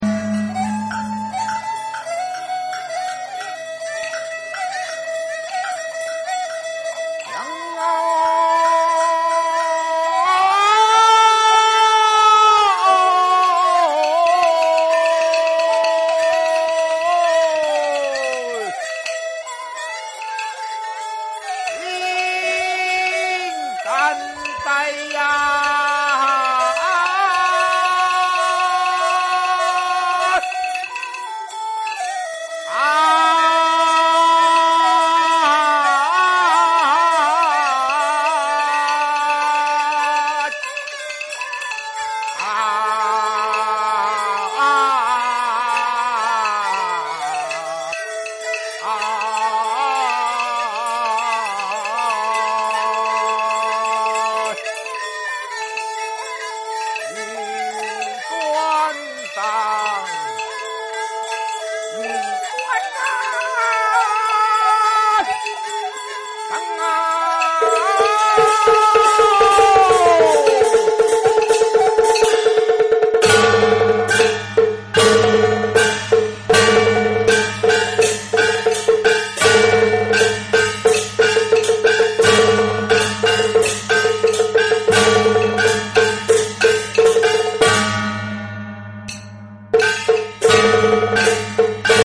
架造【彩板】 北管古路戲曲 梨春園早期錄音資料計畫